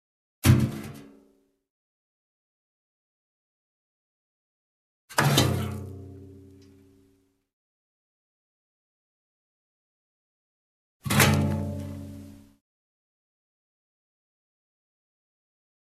Газ звуки скачать, слушать онлайн ✔в хорошем качестве